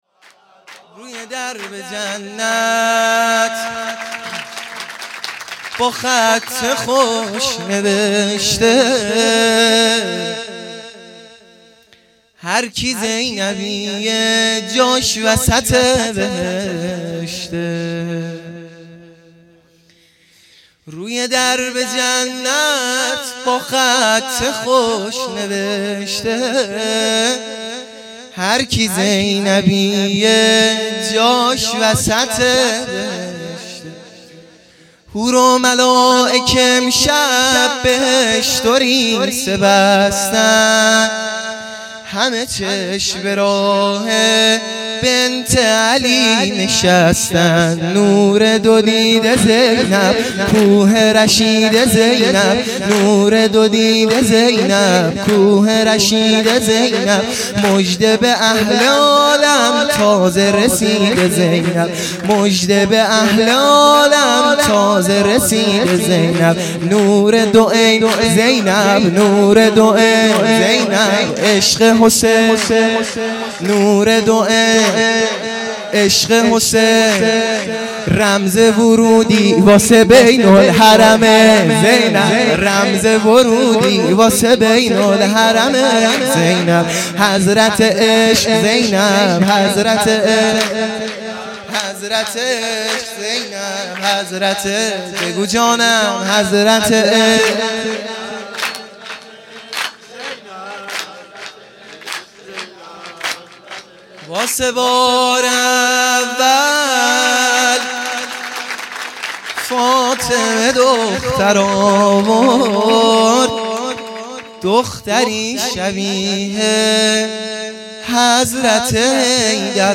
سرود | روی درب جنت با خط خوش
میلاد حضرت زینب سلام الله